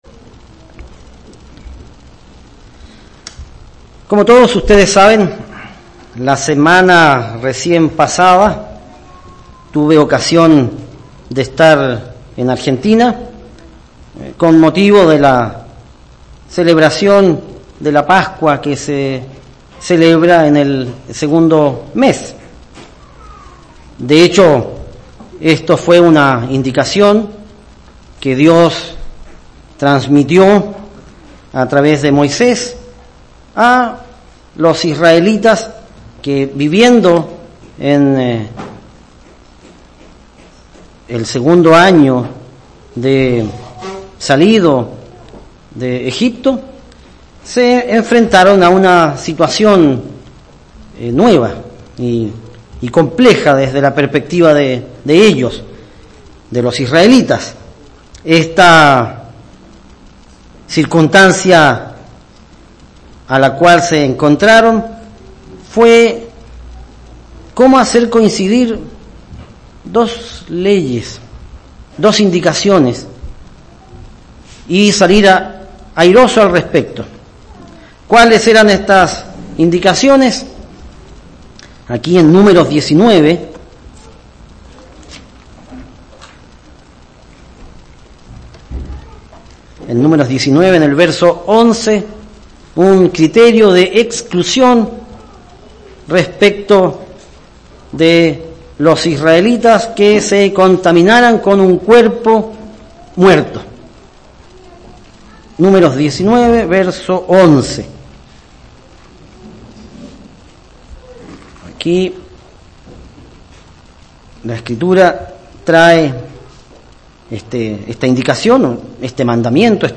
Tratar de cumplir con las ordenanzas de Dios el Padre implica que tendremos que tomar decisiones. Y de hecho, mientras más fiel se intenta ser, más dilemas se presentan en nuestras vidas. Mensaje entregado el 5 de mayo de 2018.